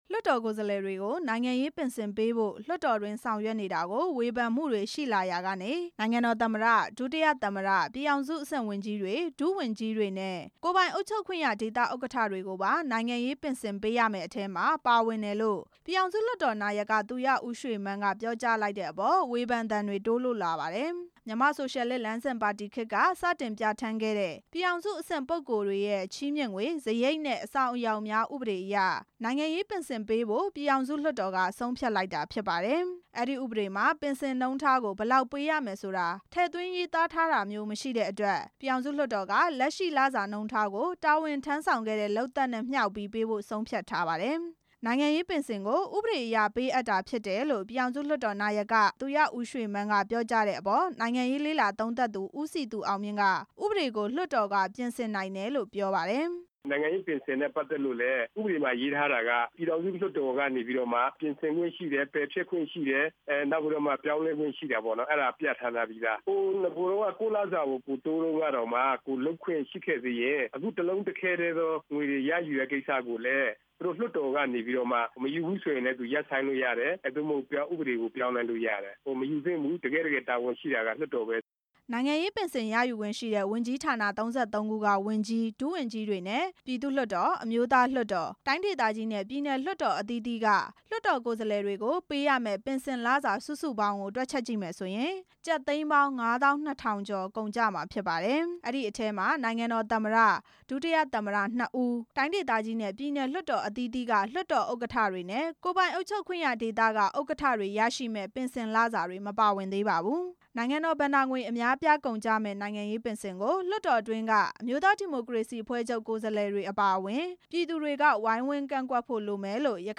အဲဒီ ဥပဒေကြမ်းကို ပြည်သူ့လွှတ်တော်မှာ ပြည်ထဲရေးဝန်ကြီးဌာနက မနေ့က တင်သွင်းခဲ့တာနဲ့ ပတ်သက် ပြီး ပြည်သူ့လွှတ်တော်မှာ သတင်းထောက်တွေရဲ့ မေးမြန်းချက်ကို ဦးဝင်းထိန်က အခုလိုပြောဆိုလိုက် တာ ဖြစ်ပါတယ်။